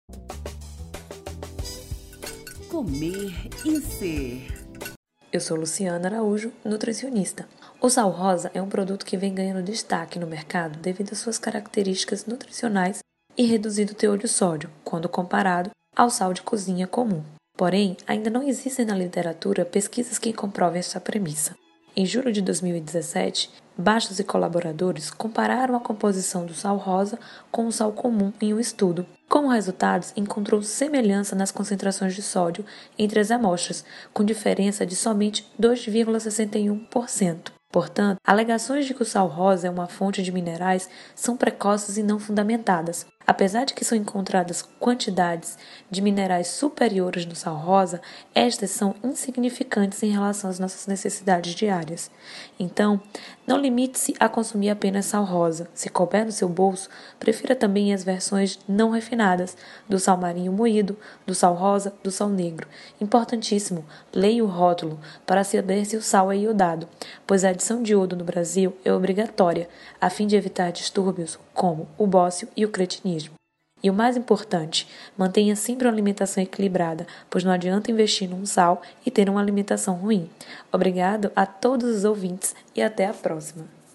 O assunto foi tema do Quadro “Comer e Ser” desta segunda-feira (30/10), no Programa Saúde no Ar.